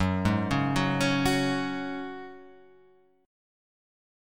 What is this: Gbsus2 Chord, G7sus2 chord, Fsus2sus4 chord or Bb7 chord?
Gbsus2 Chord